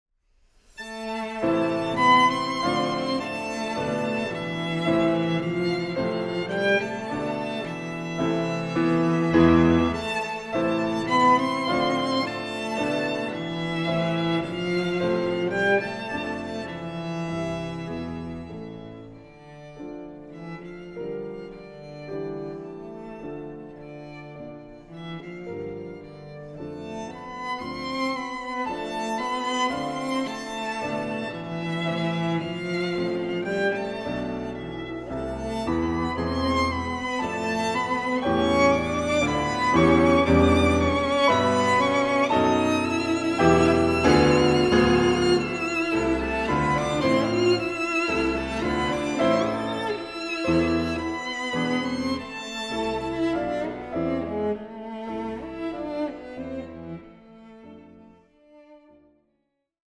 Aufnahme: Festeburgkirche Frankfurt, 2024
II. Andante con moto